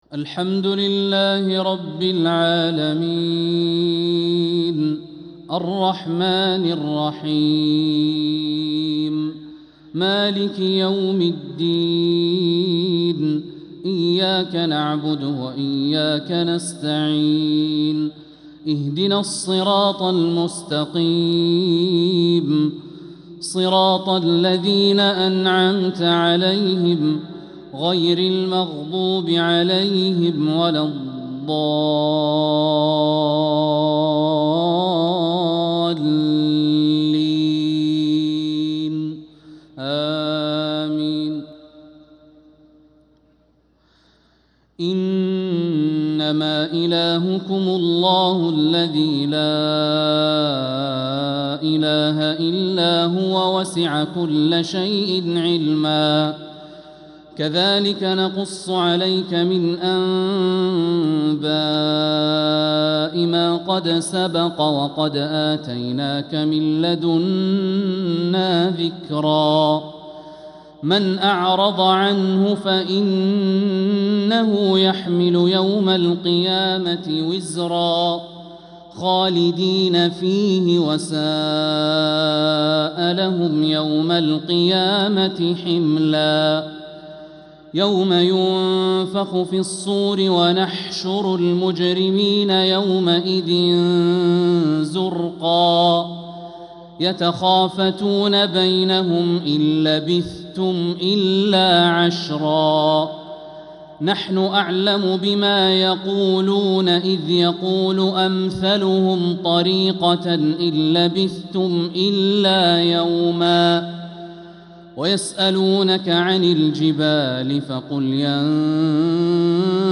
عشاء السبت 2-8-1446هـ من سورة طه 98-114 | Isha prayer from Surat Ta Ha 1-2-2025 > 1446 🕋 > الفروض - تلاوات الحرمين